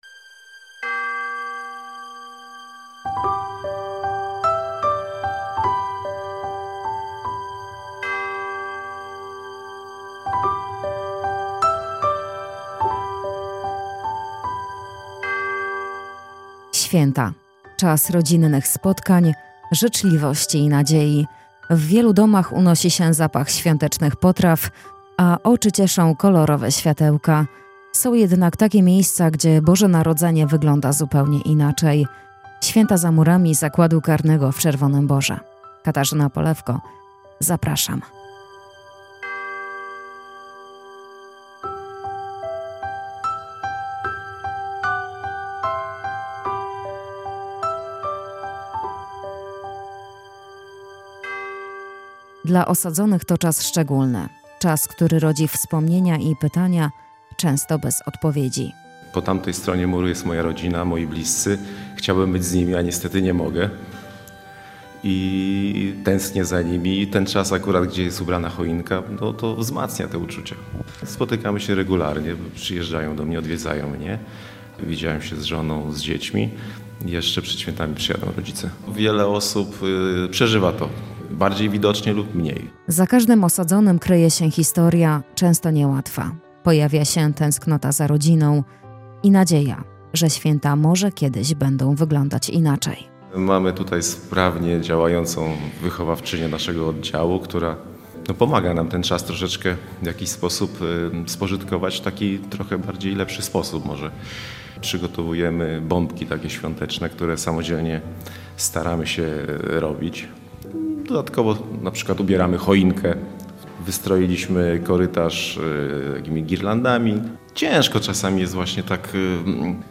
Świąteczny reportaż RN: Boże Narodzenie w Zakładzie Karnym
Materiał został zrealizowany w Zakładzie Karnym w Czerwonym Borze.